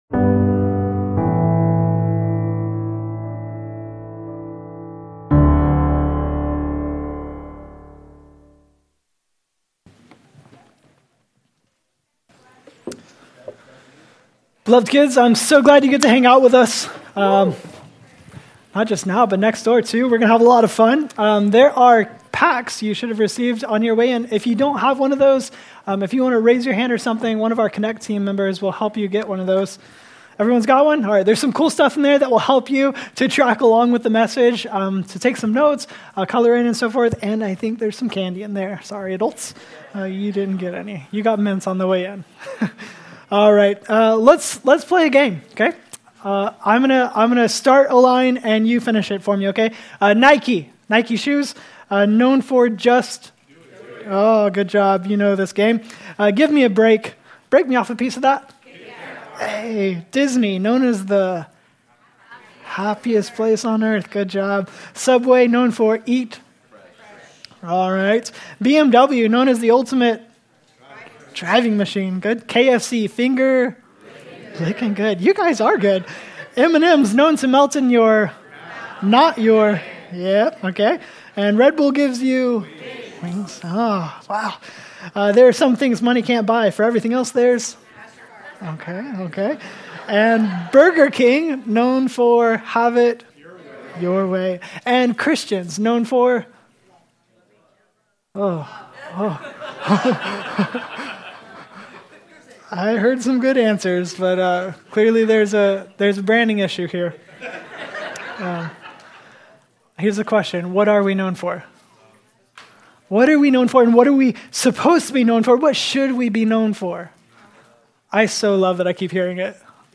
Hear this year’s annual Connect Sunday message, BE ON MISSION, here.